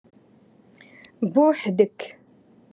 Moroccan Dialect- Rotation Three- Lesson Five